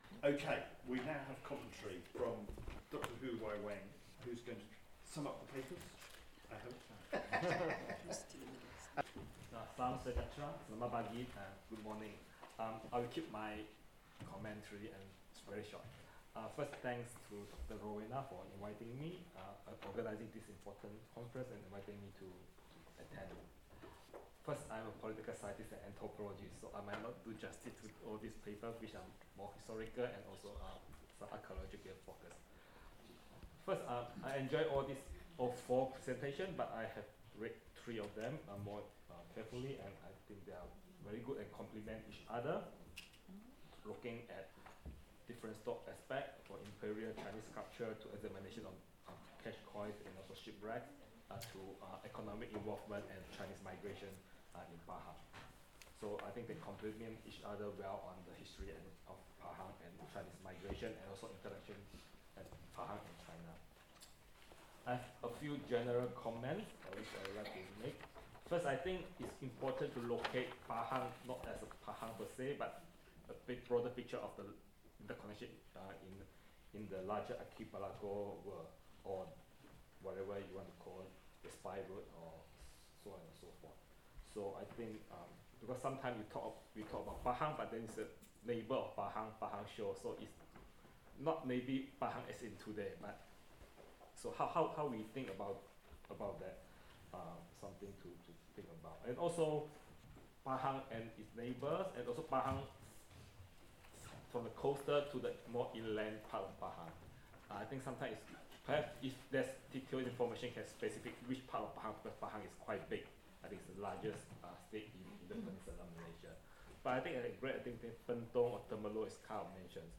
Commentary Malaysia workshop, Day 2 Panel 1, Pahang, China and The Spice Route 6:16